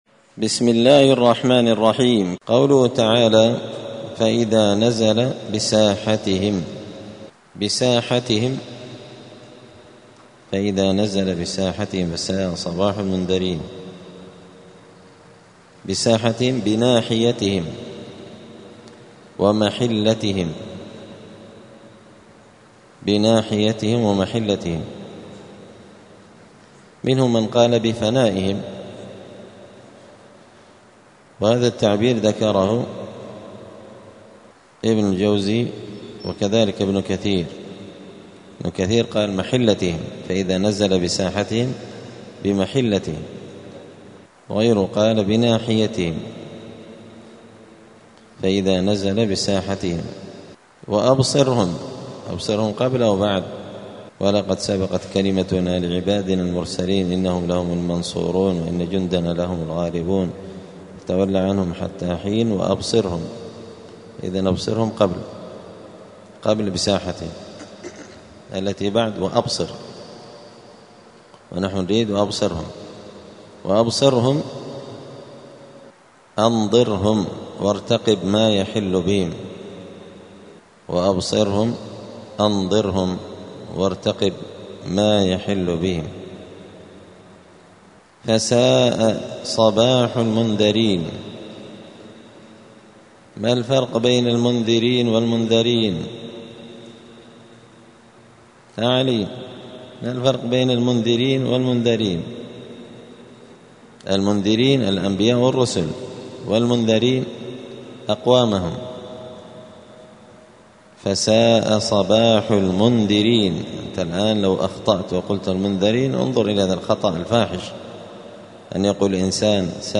زبدة الأقوال في غريب كلام المتعال الدرس الثاني والتسعون بعد المائتين (292)